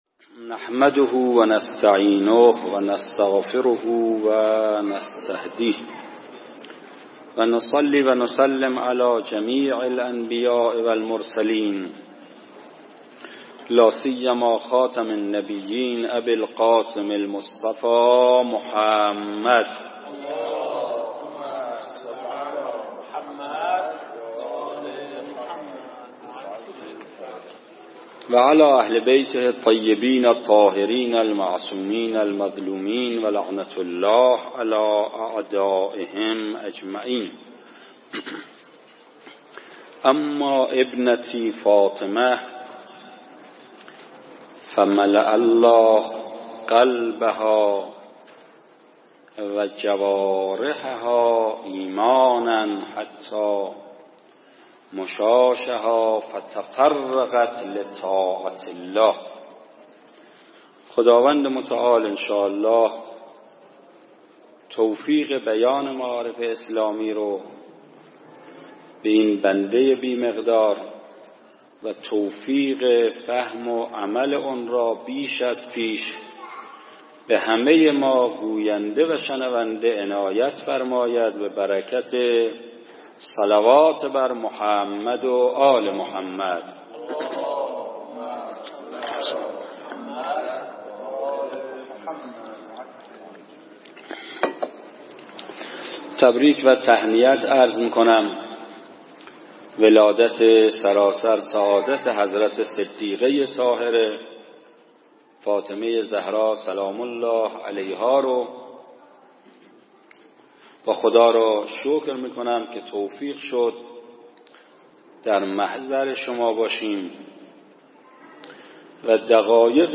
💐🌸 جشن خانوادگی میلاد حضرت زهرا سلام الله علیها